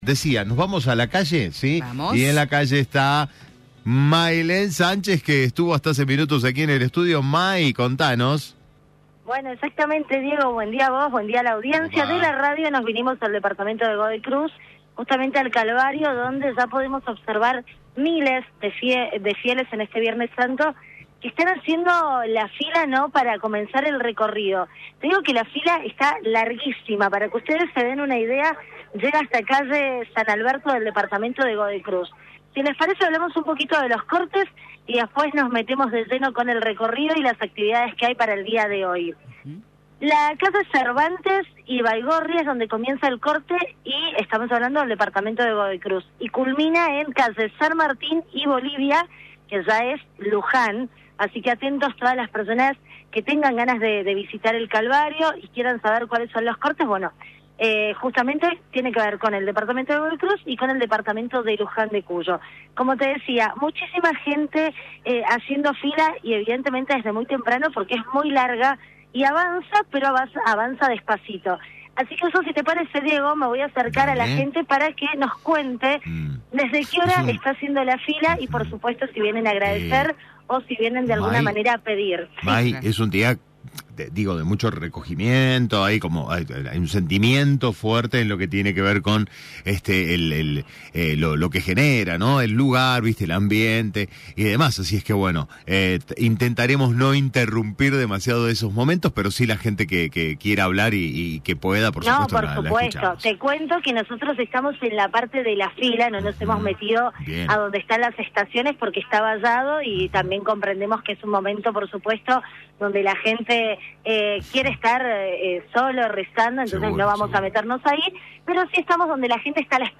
LVDiez - Radio de Cuyo - Móvil LVDiez desde El Calvario